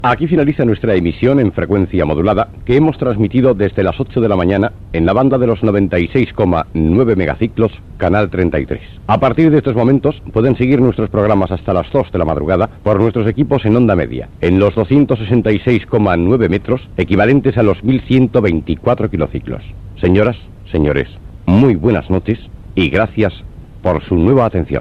Final d'emissions nocturn de l'FM de Radio España de Barcelona, als 96.9 MHz.